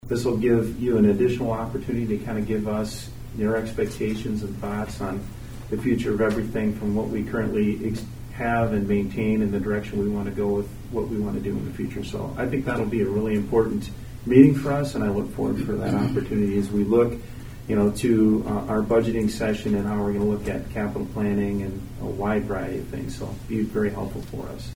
Aberdeen City Manager Dave McNeil: